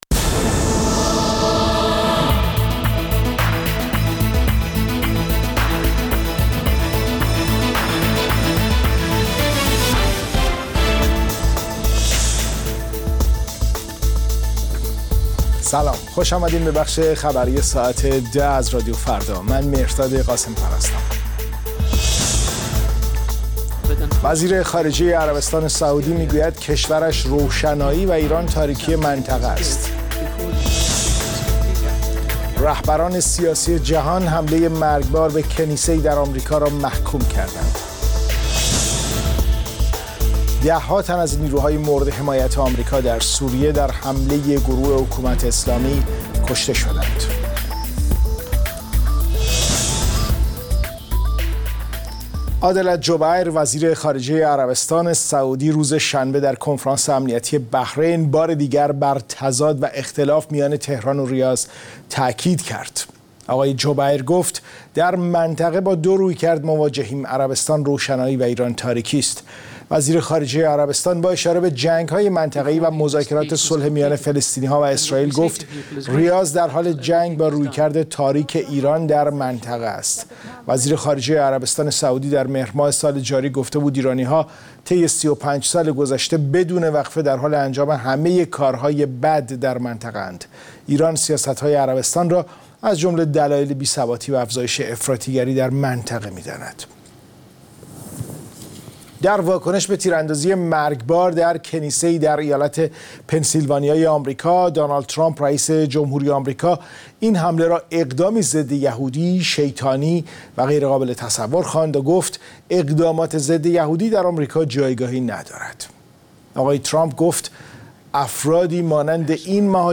اخبار رادیو فردا، ساعت ۱۰:۰۰